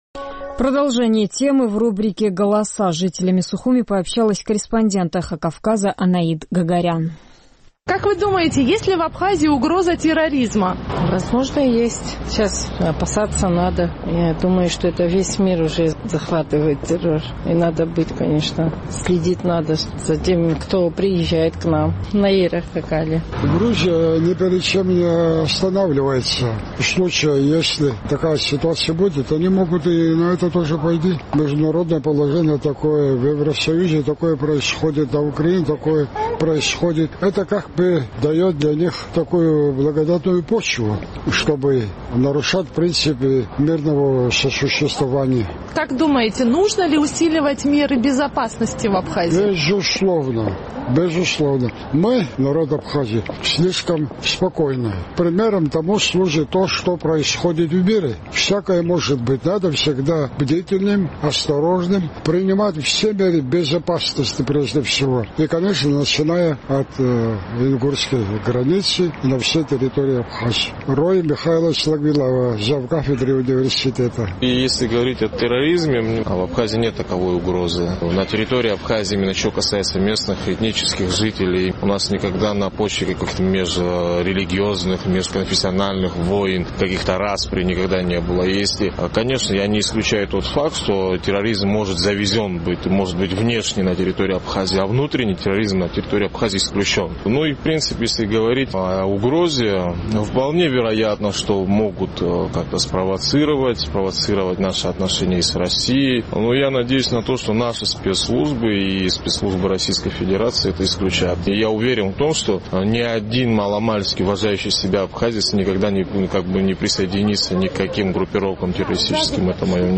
Наш сухумский корреспондент поинтересовалась у жителей абхазской столицы, есть ли в республике угроза терроризма.